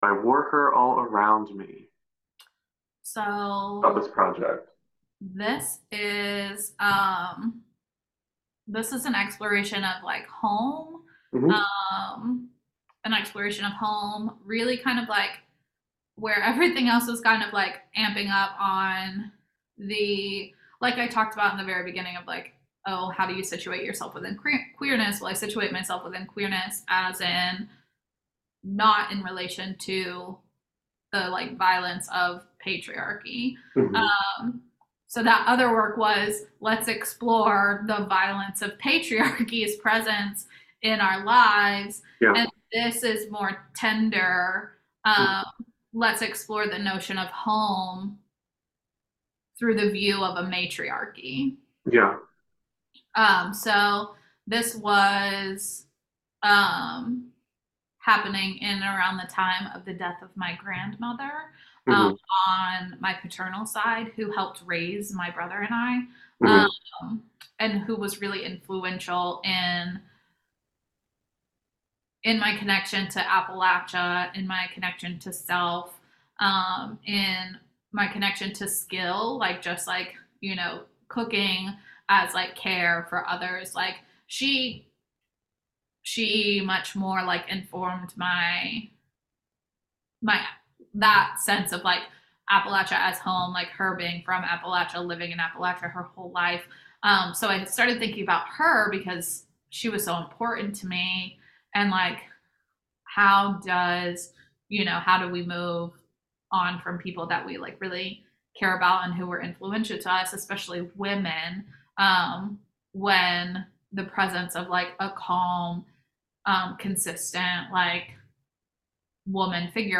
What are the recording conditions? over Zoom